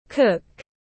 Đầu bếp tiếng anh gọi là cook, phiên âm tiếng anh đọc là /kʊk/.
Cook /kʊk/